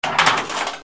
Rumore metallico
Suono metallico corto tipo palla del flipper che esce per il tiro o macchina meccanica in funzione.